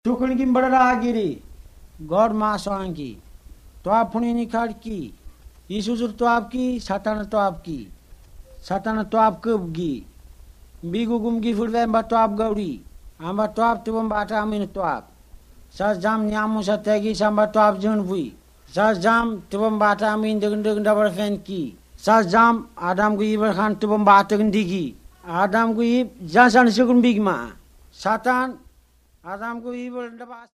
Words of Life recordings contain short Bible stories, evangelistic messages and songs. They explain the way of salvation and give basic Christian teaching. Most use a storytelling approach. These are recorded by mother-tongue speakers